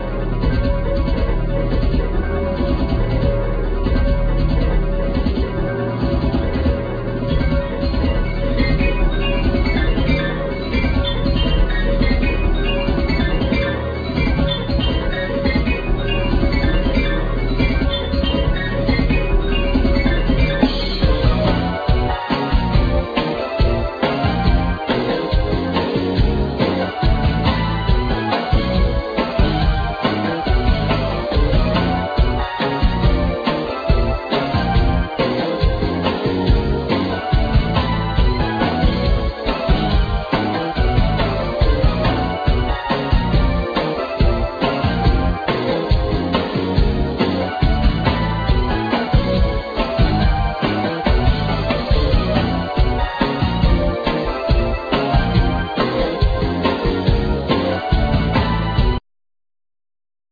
Vocal,Synthsizer,Harmonica
Drums,Keyboards
Guitar,Mandlin
Bass,Melodica